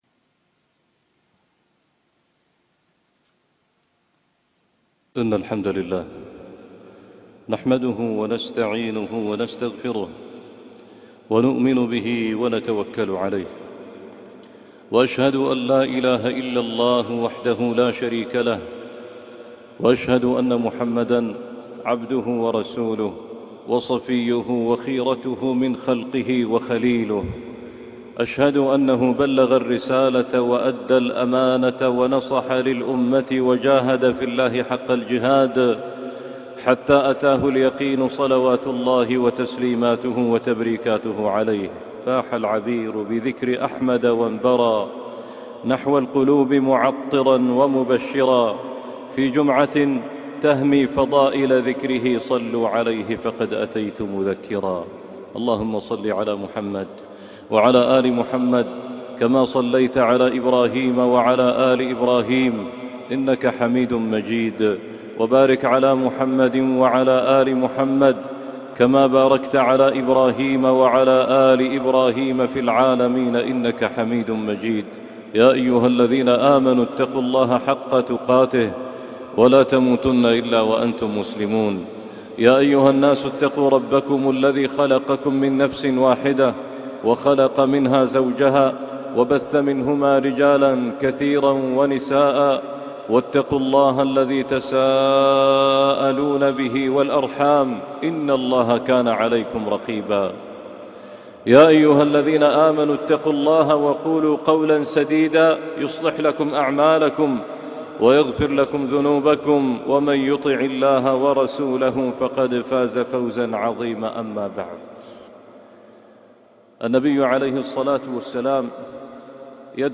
يومٌ بأيام - خطبة وصلاة الجمعة